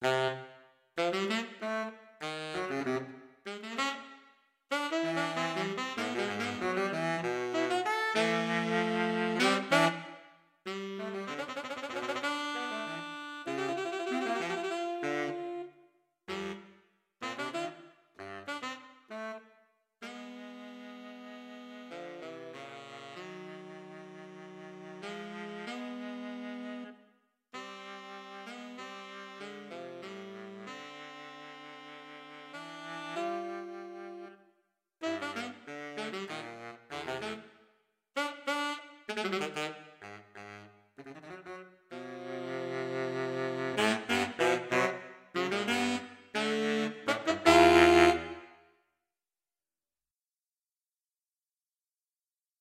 Based on a simple interval set: P4 - 2M - 3m